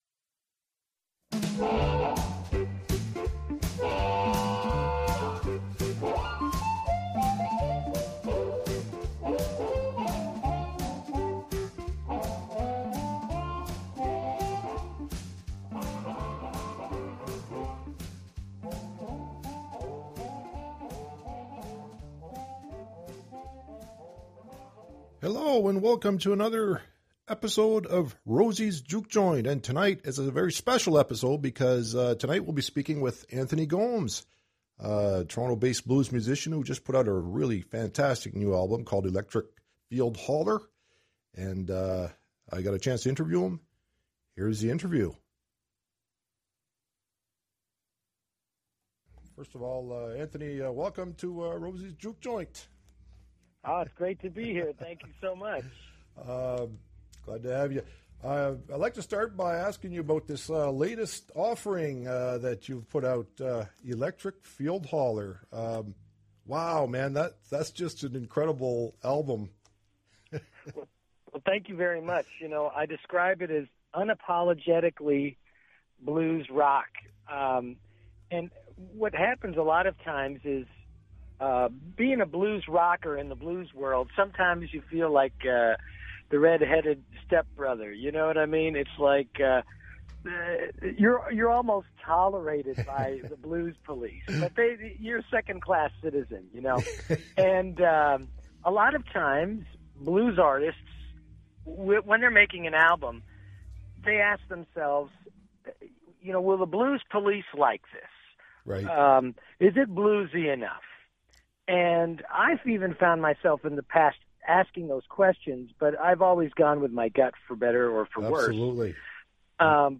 Best in Canadian Blues and Beyond!